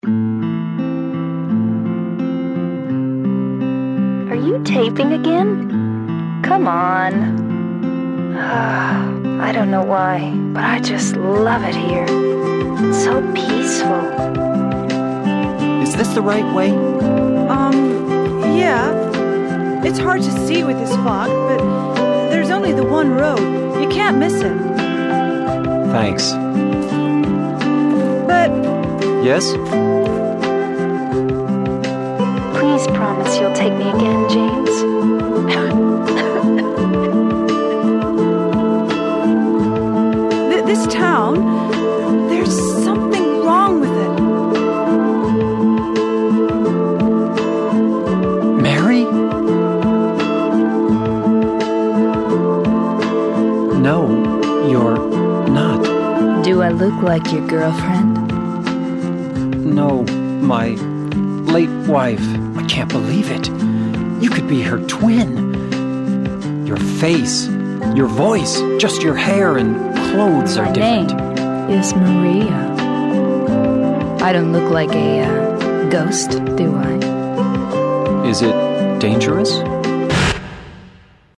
Groove